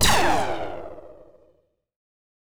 fire_laser2.wav